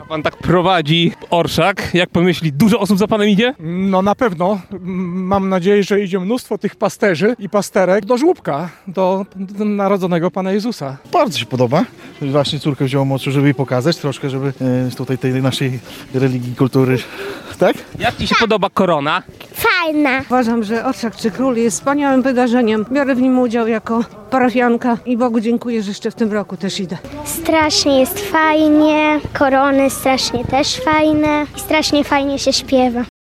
O tym, że Orszak był udany przekonywali także jego uczestnicy.